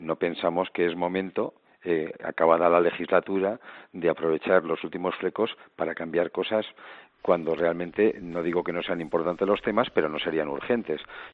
En declaraciones a COPE el líder de la patronal ha dejado claro que “no es el momento de hacer decretos leyes electorales” y así se lo va a transmitir a la ministra de Trabajo, Magdalena Valerio.